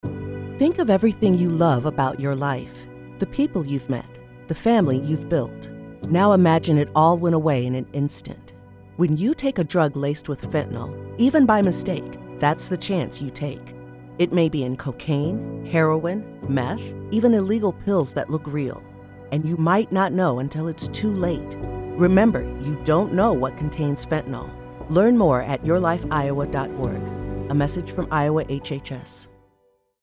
:30 Radio Spot | Fentanyl | 30 + Female
Radio spot :30 Radio Spot | Fentanyl | 30 + Female Most people have heard of fentanyl, but they may not understand how dangerous of a threat it poses to them.